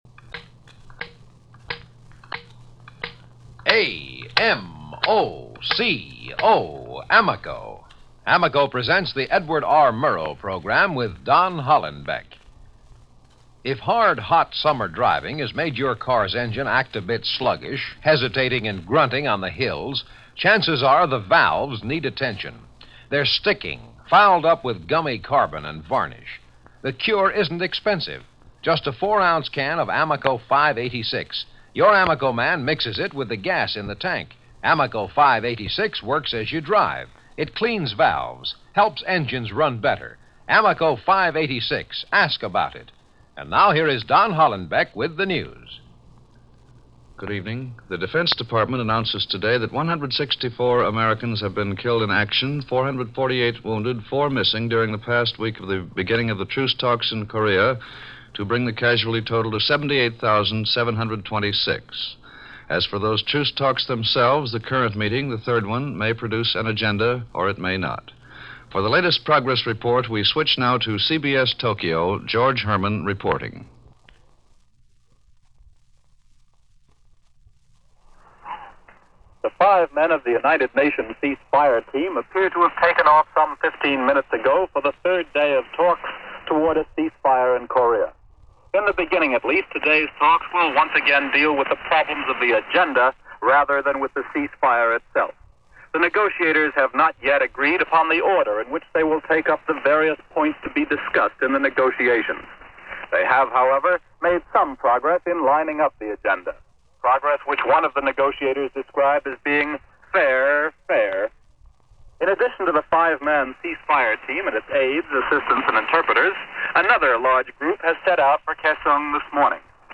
CBS Radio: Edward R. Murrow News with Don Hollenbeck